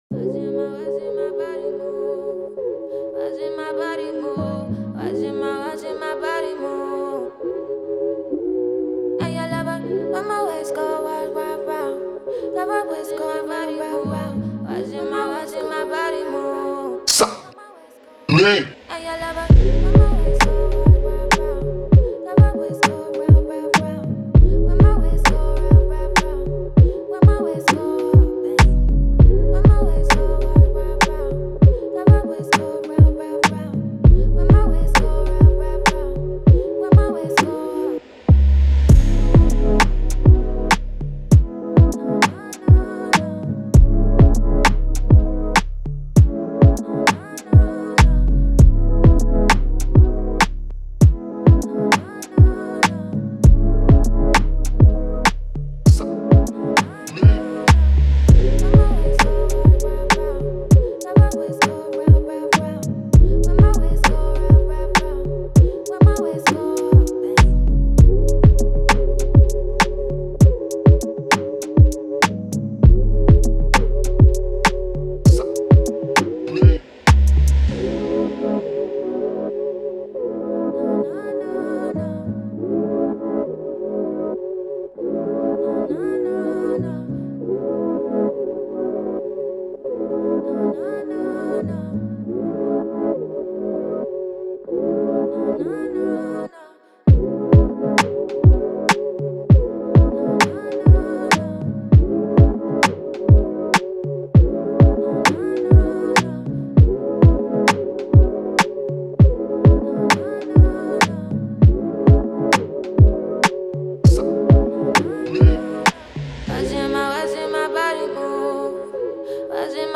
Positive, Sexy, Vibe
Lead, Heavy Bass, Strings, Vocal